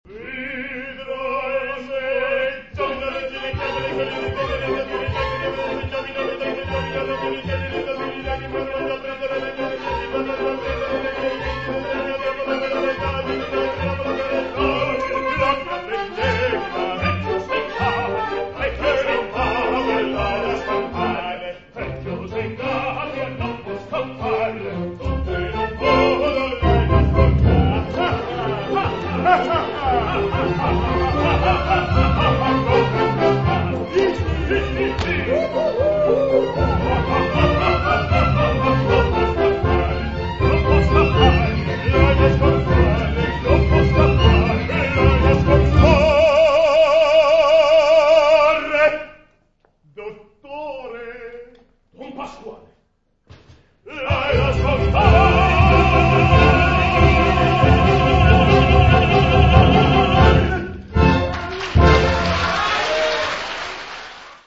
Piccolo archivio multimediale delle passate stagioni artistiche del teatro Verdi di Buscoldo.